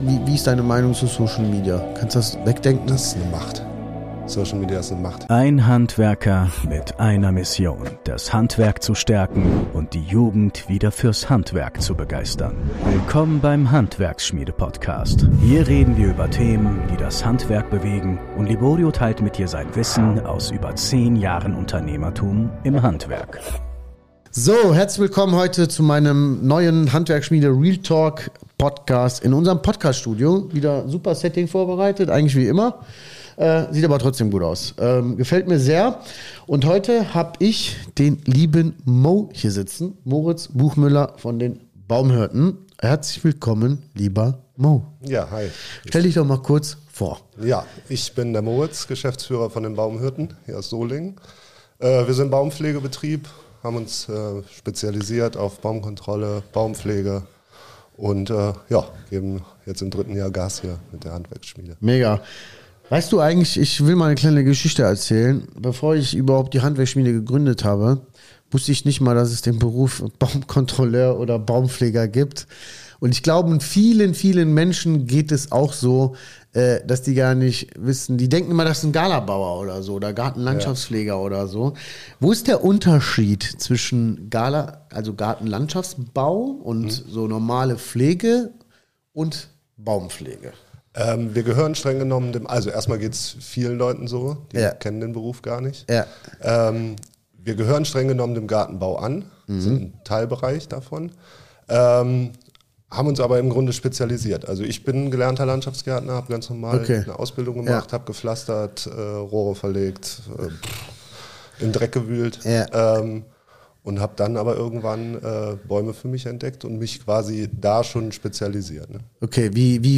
So bin ich in meinem Unternehmen frei geworden | Interview